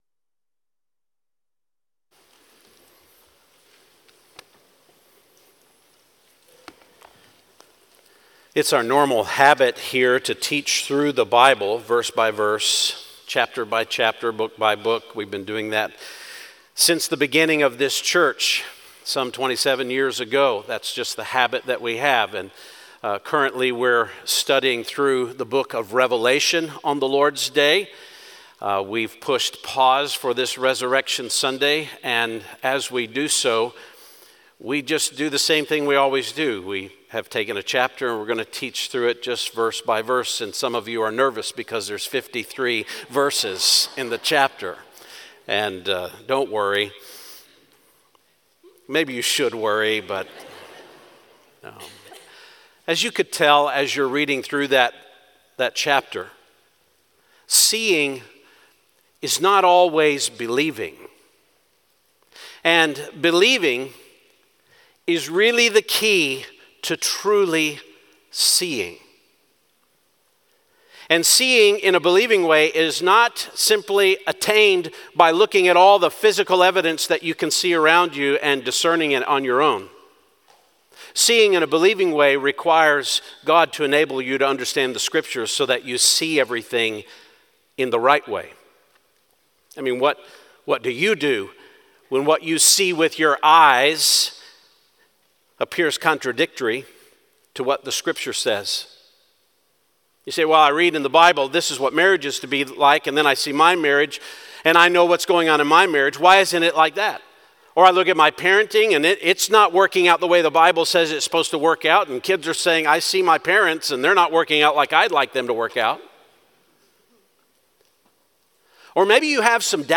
Sermons
Sermons from Summit Woods Baptist Church: Lee's Summit, MO